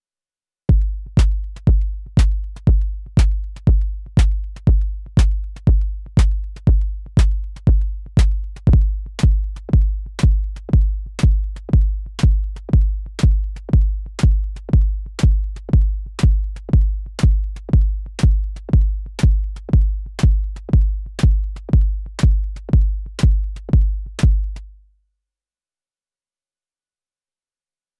I send you a sound where you can hear clearly the delay when sending a PC from Keystep pro to Syntakt when using the preset you proposed to test (with the test of Delay 20ms and Sync Delay by -1). (Kick is coming from Syntakt = I only programmed a Kick on Syntakt / The percussion is coming from Moog DFAM synth which is synced (clock out) by Keystep pro)